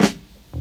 07_Snare_10_SP.wav